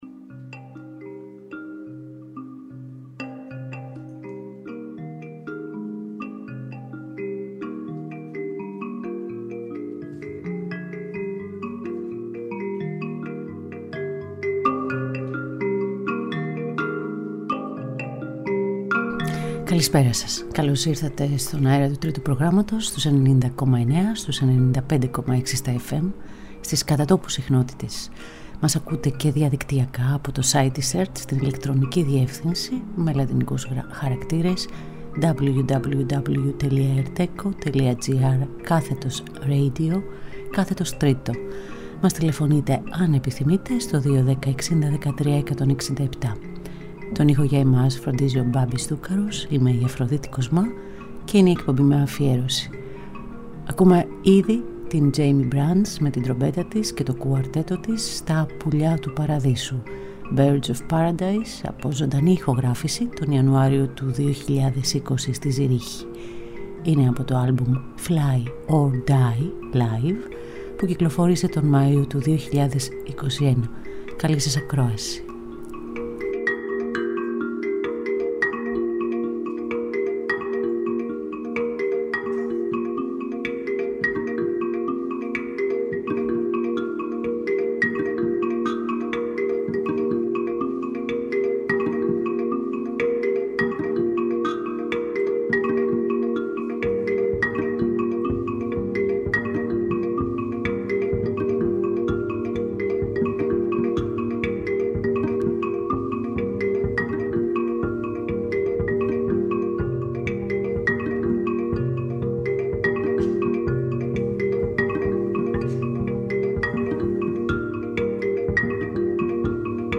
Λικνιζόμαστε στα υπνωτικά ηχοχρώματα μιας νέγρικης φιγούρας.
Ζωντανά από το στούντιο του Τρίτου Προγράμματος 90,9 & 95,6